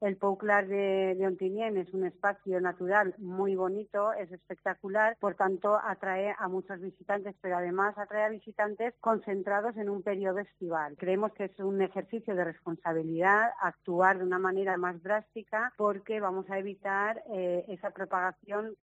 Sayo Gandia, concejal de Ontinyent: "El cierre del Pou Clar es un ejercicio de responsabilidad"